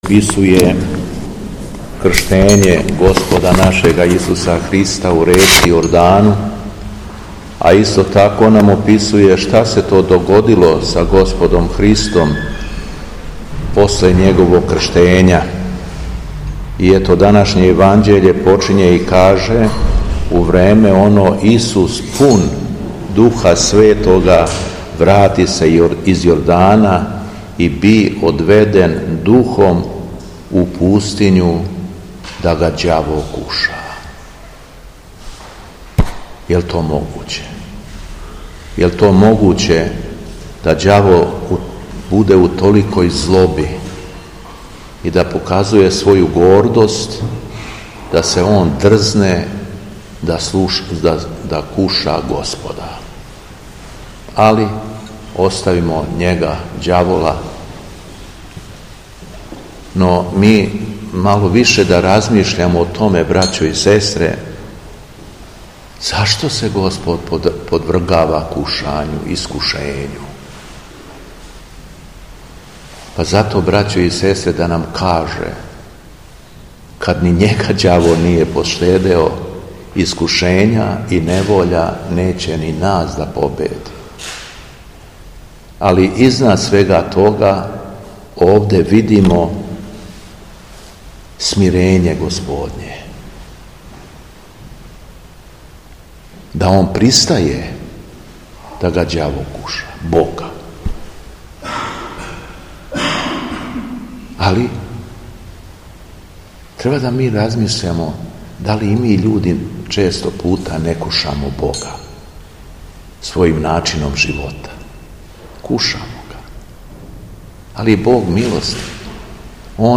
Беседа Његовог Високопреосвештенства Митрополита шумадијског г. Јована
У среду 8. октобра 2025. године, када Црква молитвно прославља Преподобну Ефросинију и Преподобног Сергија Радоњешког, Његово Високопреосвештенство Митрополит шумадијски Господин Јован служио је свету архијерејску литургију у храму Светога великомученика кнеза Лазара у Белошевцу уз саслужење братсва храма.